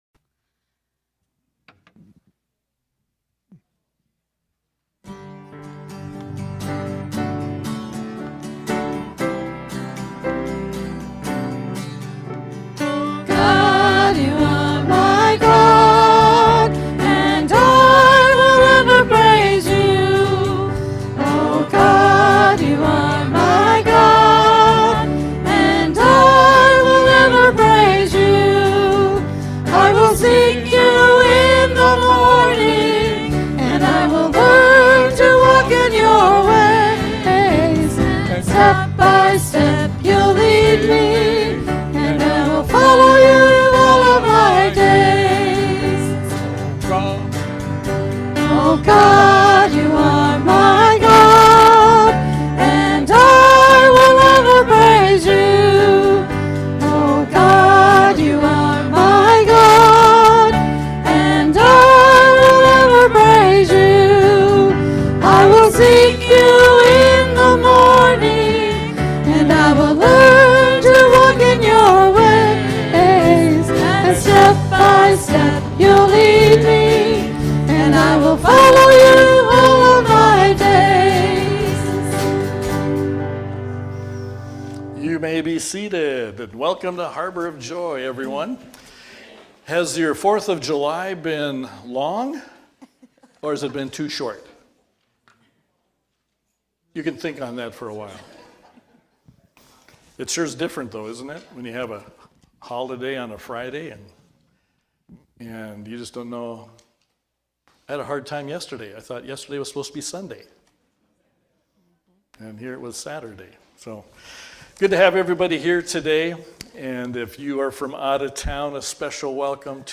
Worship-July-6-2025-Voice-Only.mp3